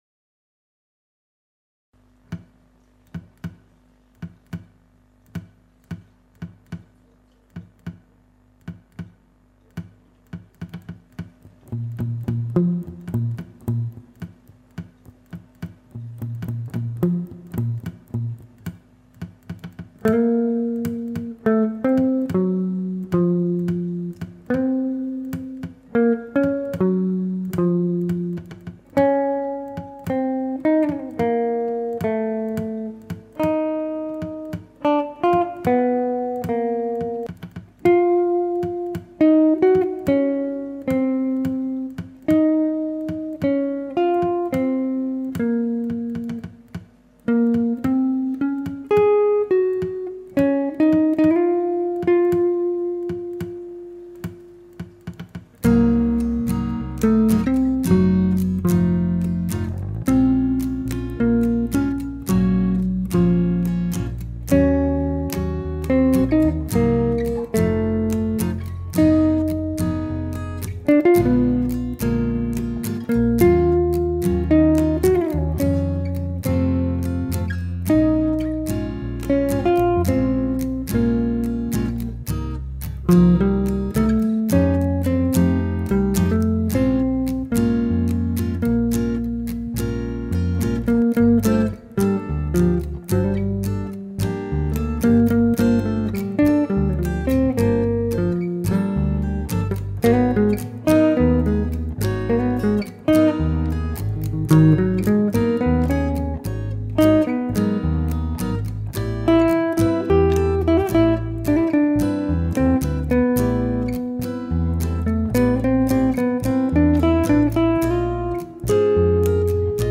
Chitarre varie, basso